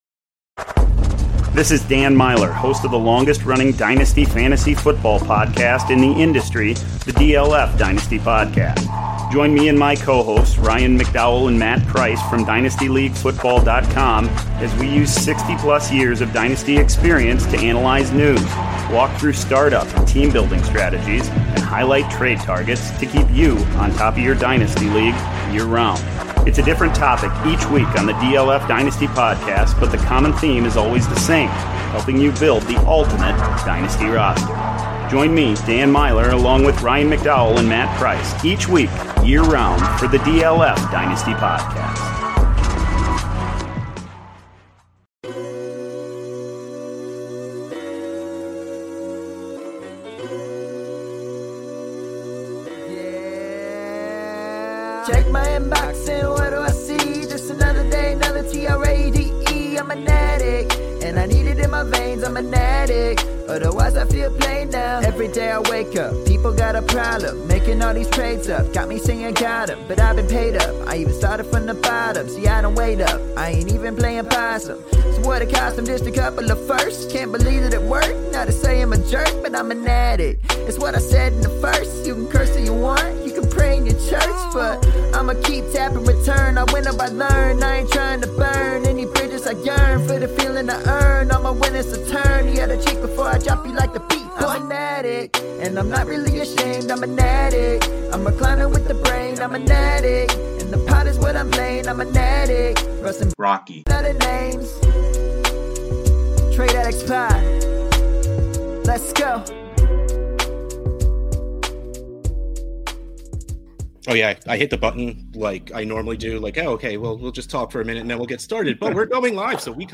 it was live.